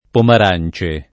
pomar#n©e] top. (Tosc.) — antiq. le Pomarance; nell’uso più ant., Ripomarance [ripomar#n©e] — es.: il colle delle Pomarance [